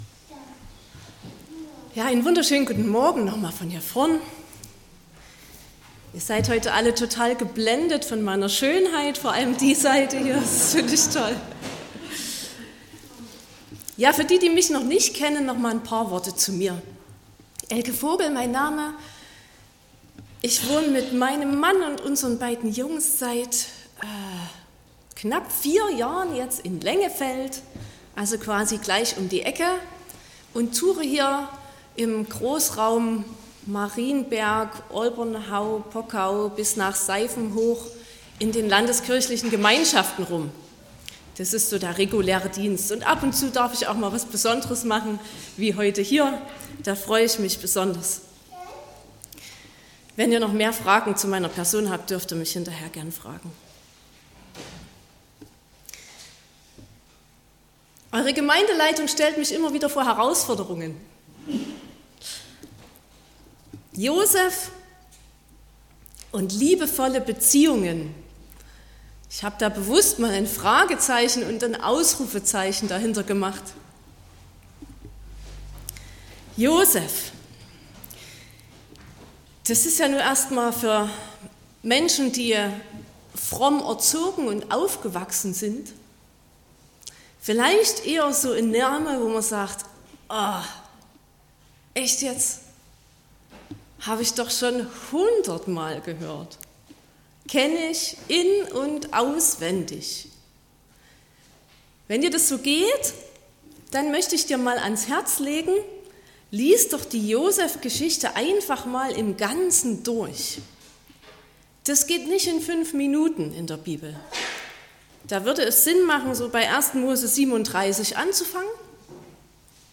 09.03.2025 – Gottesdienst
Predigt und Aufzeichnungen